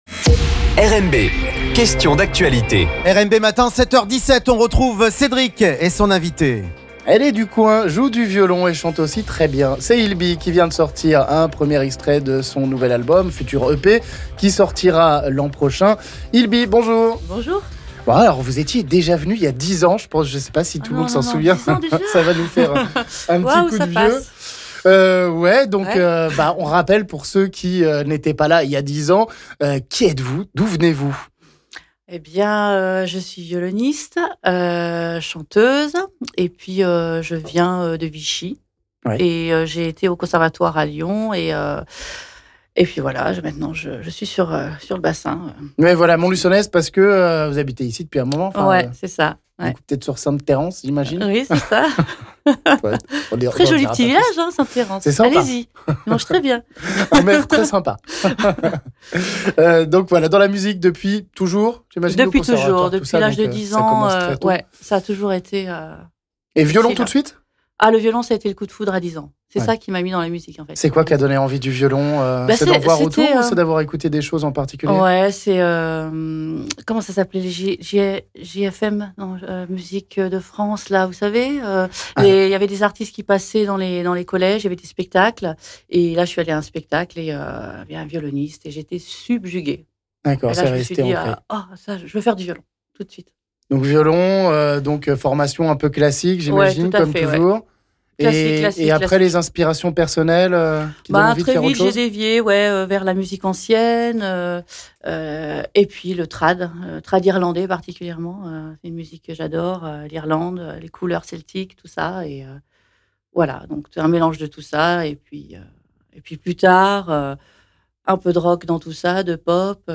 est venue enchanter le studio d'RMB ce vendredi avec son univers musical et son violon